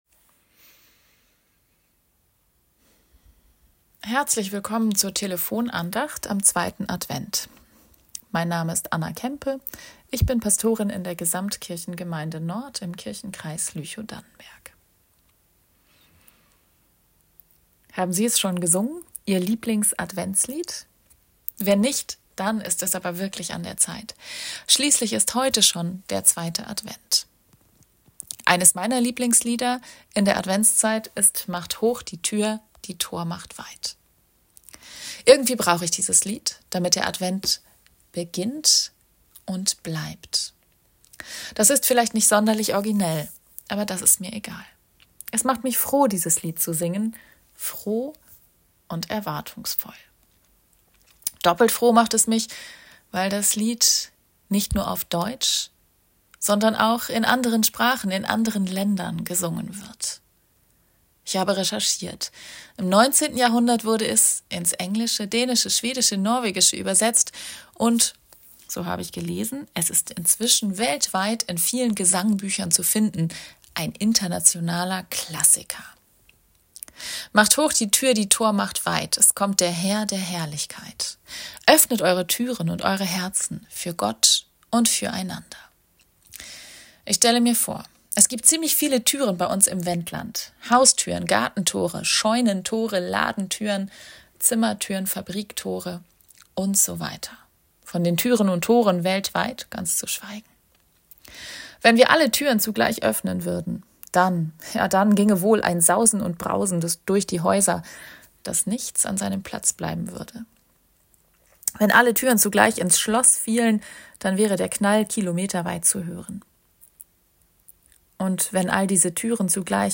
Türen und Herzen ~ Telefon-Andachten des ev.-luth. Kirchenkreises Lüchow-Dannenberg Podcast